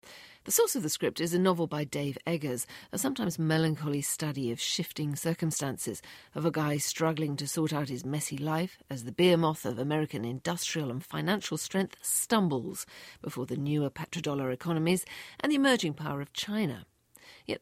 【英音模仿秀】《国王的全息图》 听力文件下载—在线英语听力室